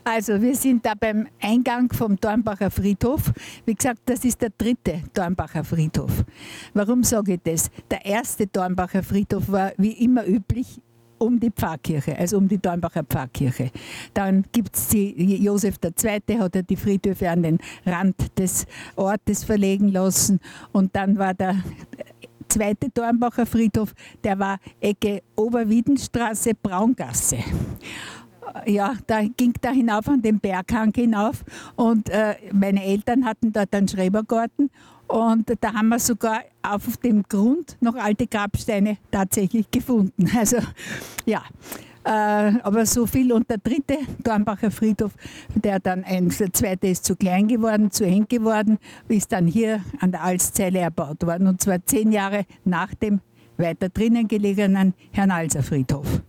gehcafe-hernals-dritter-dornbacher-friedhof.mp3